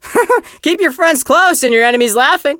chester_lead_vo_03.ogg